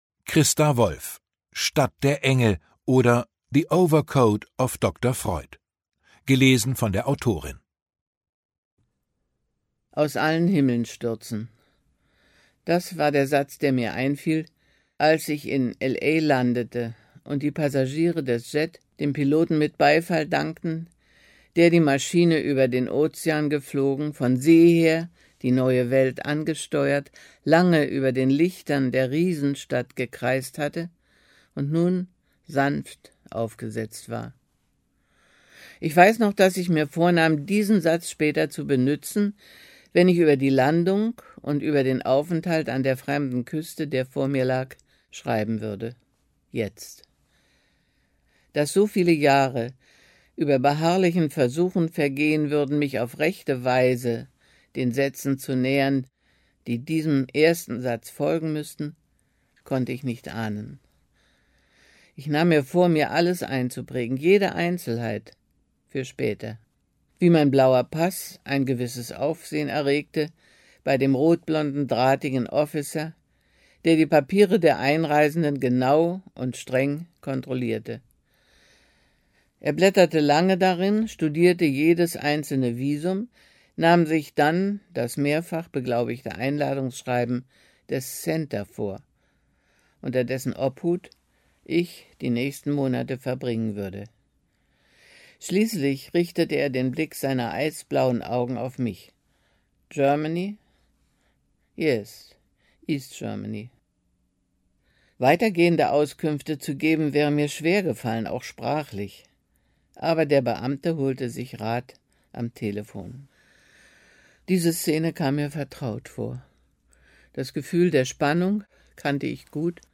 2021 | Gekürzte Lesung
Eine berührende Identitätssuche und kraftvolle Lebensbejahung – gelesen von der Autorin selbst.